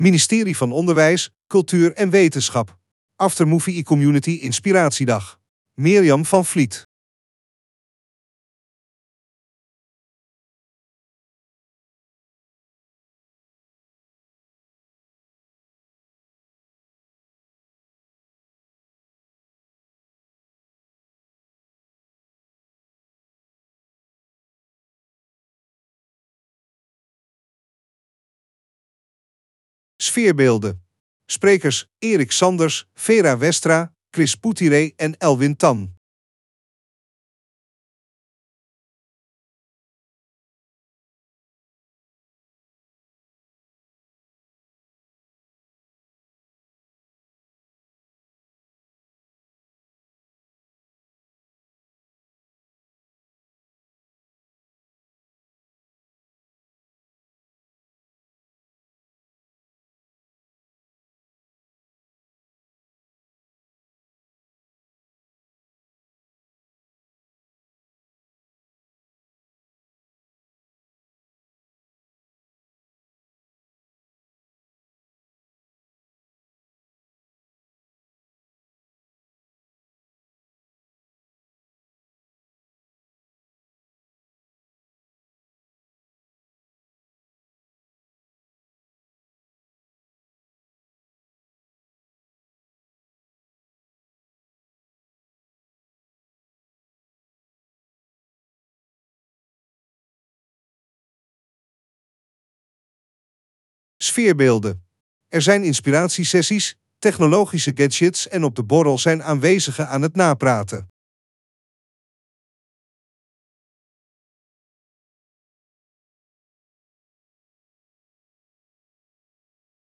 Locatie: Hoftoren, Den Haag. ♪ LICHT OPZWEPENDE MUZIEK ♪